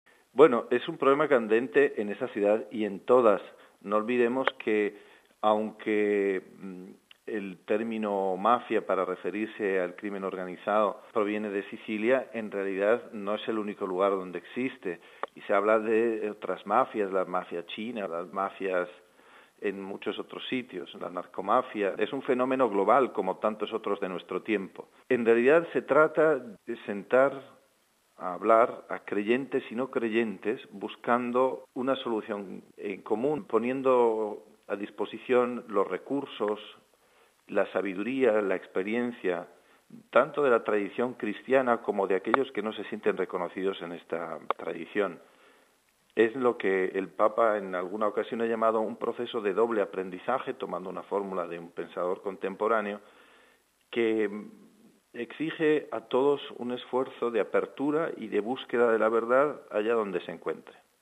Una iniciativa patrocinada por el Pontificio Consejo para la Cultura, con el objetivo de acercar los visitantes a la gran historia de la Biblia. Con nosotros el subsecretario del dicasterio Mons Melchor Sanchez de Toca.